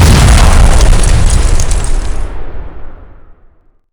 defensiveSystemsPenetrated.wav